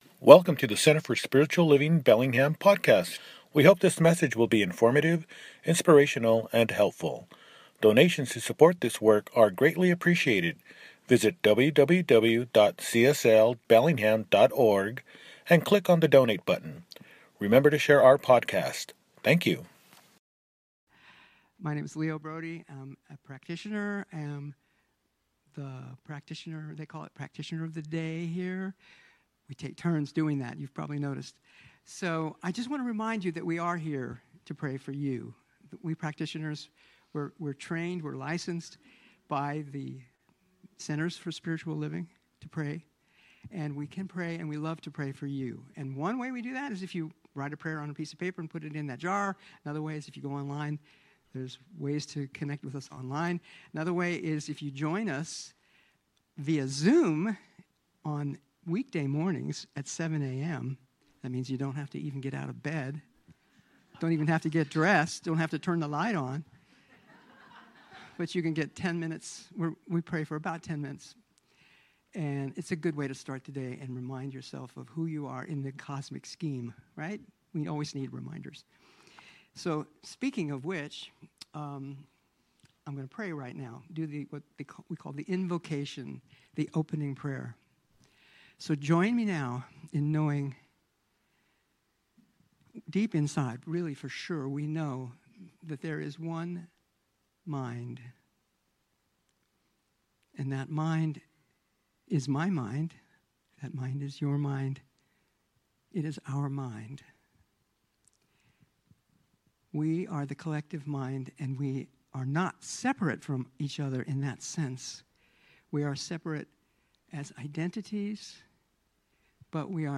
The Voice of Silence – Celebration Service